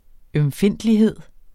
Udtale [ œmˈfenˀdliˌheðˀ ]